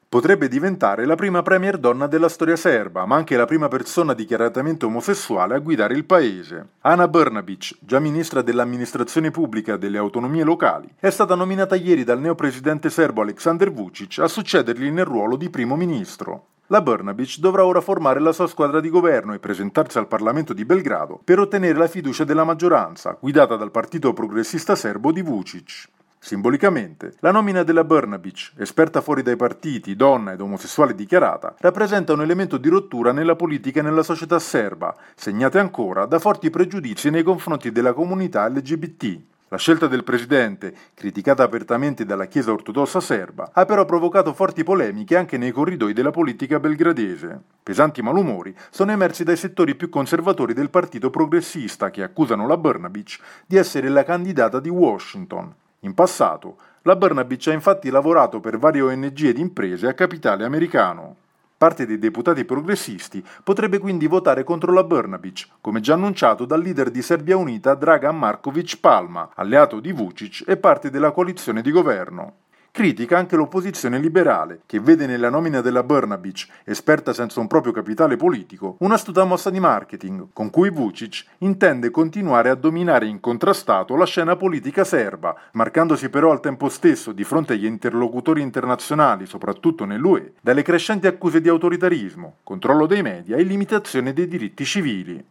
per il GR di Radio Capodistria [16 giugno 2017]